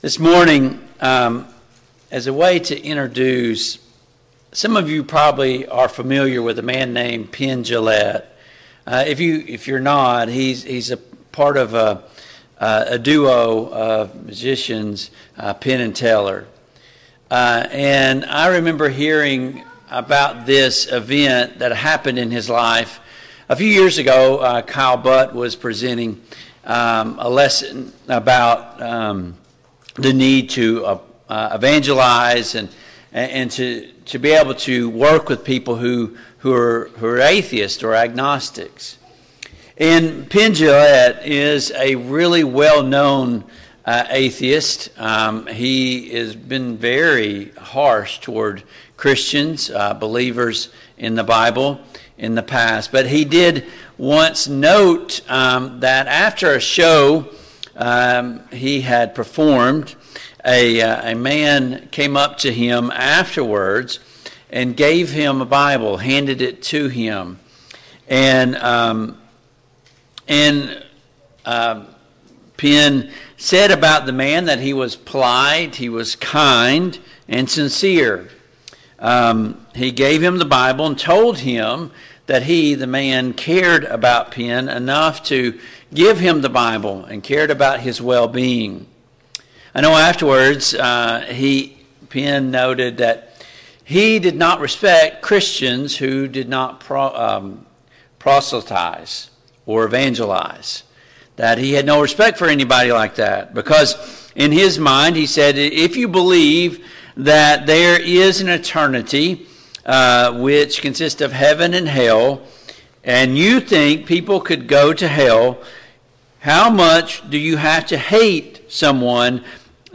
Jeremiah 20:7-9 Service Type: AM Worship Download Files Notes « 12.